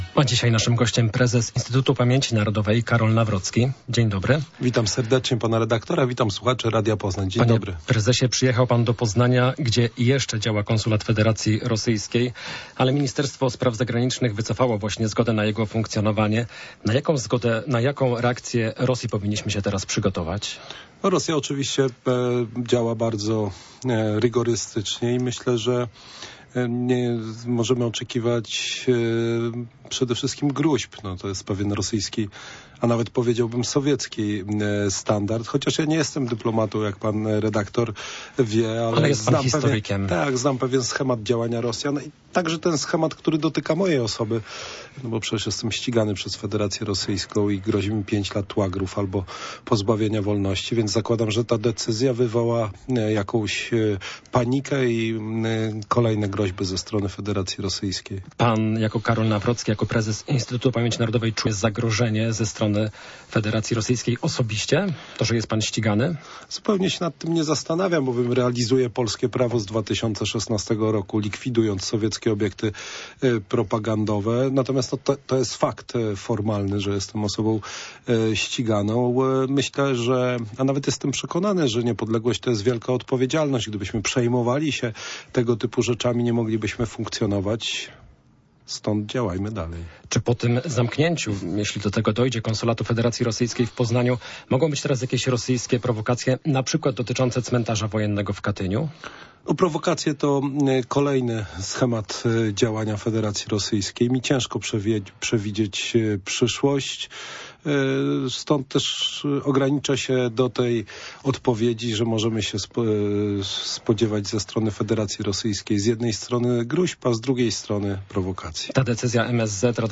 Popołudniowa rozmowa z Radiem Poznań - Karol Nawrocki
Gościem Radia Poznań jest prezes Instytutu Pamięci Narodowej Karol Nawrocki.